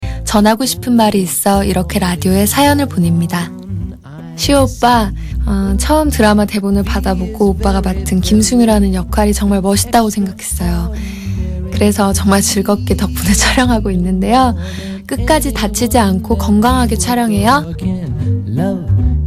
文彩元在KBS廣播 留言給施厚 音檔